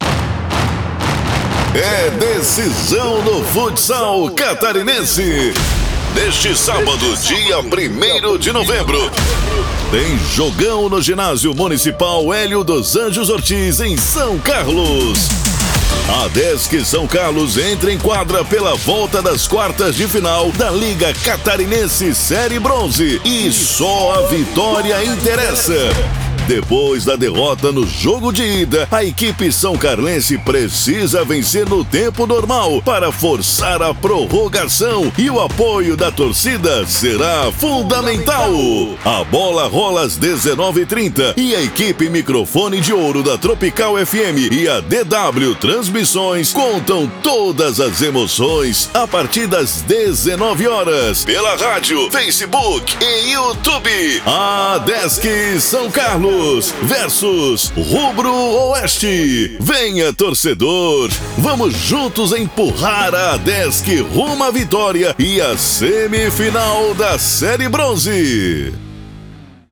Chamada ADESC SC:
Spot Comercial
Animada